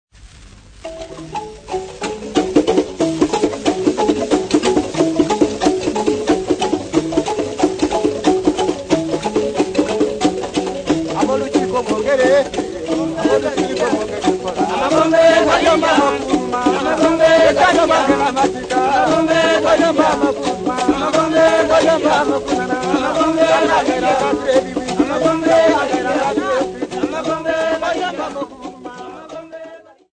Popular music--Africa
Dance music
Field recordings
sound recording-musical
Mourning song accompanied by various indigenous instruments